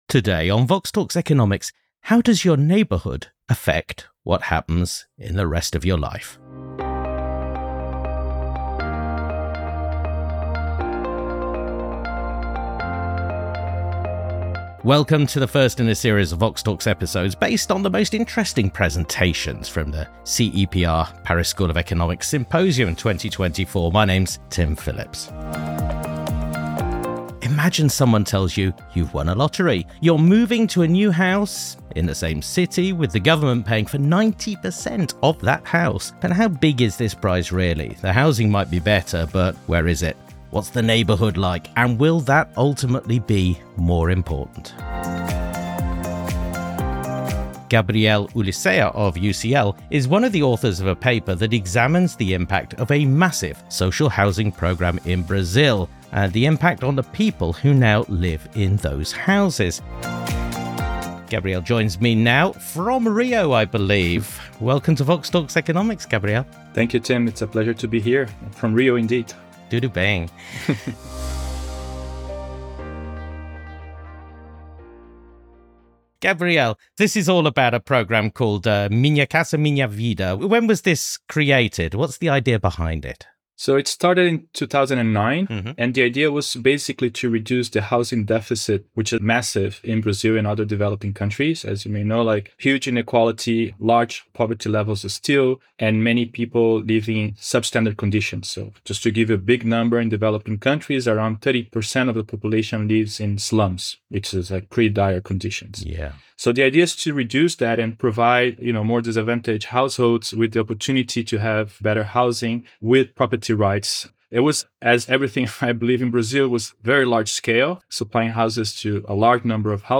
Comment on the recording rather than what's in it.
The first in a series of VoxTalks Economics based on some of the most interesting presentations from the PSE-CEPR Policy Forum 2024.